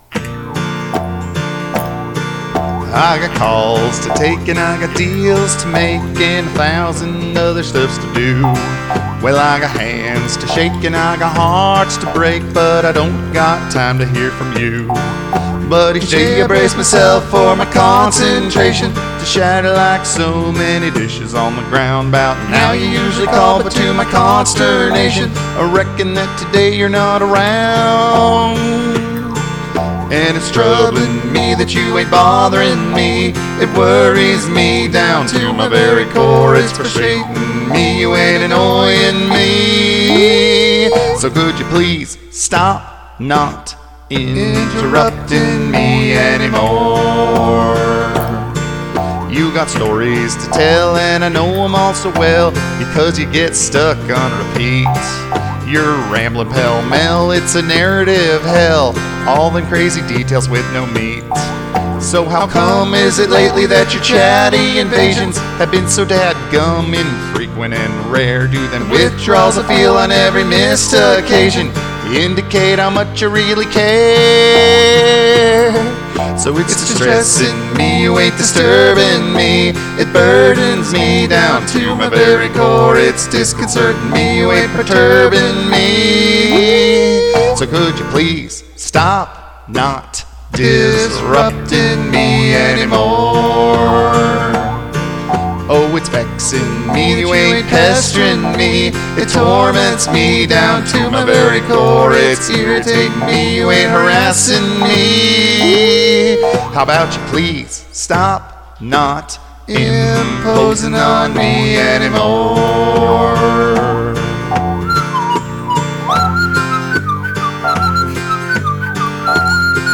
So the stress comes out in a quirky bluegrass tune, where the narrator has a considerable vocabulary, but this song was also nominated for best use of the lyric "dad-gum."